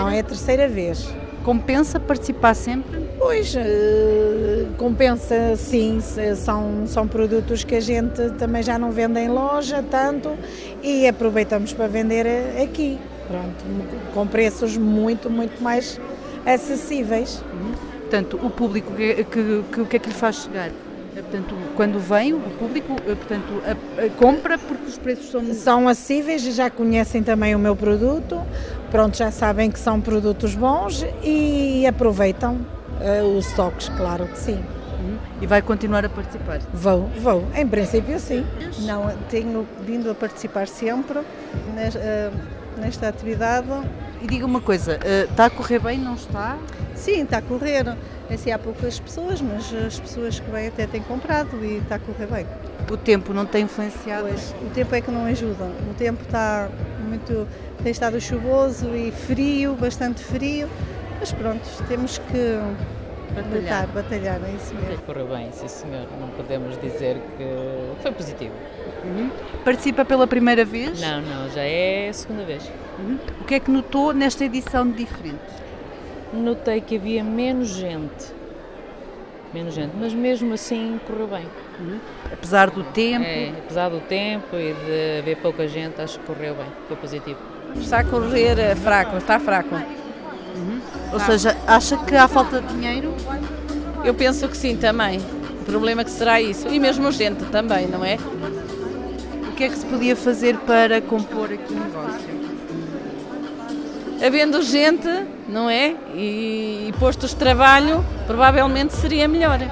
A opinião dos empresários é que que apesar da chuva que se fez sentir neste fim de semana correu bem, como adiantaram: